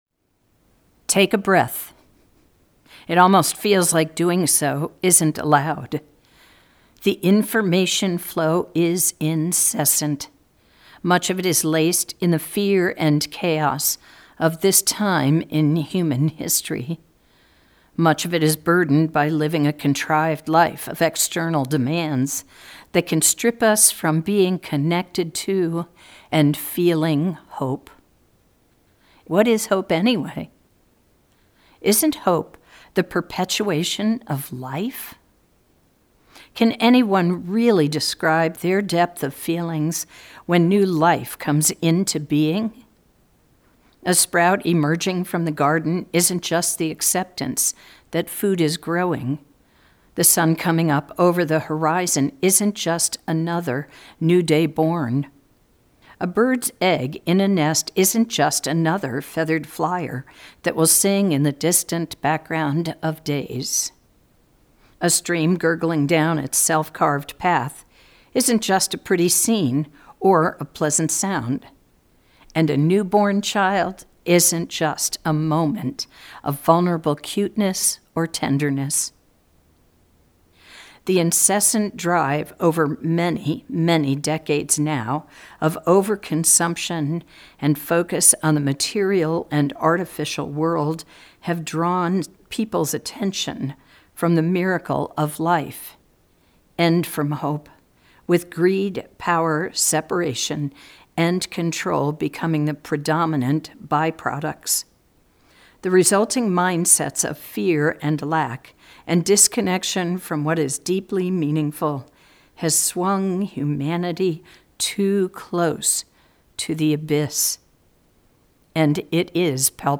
recorded her sermon in the Meeting House several days after the service